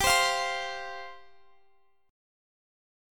Listen to F5/G strummed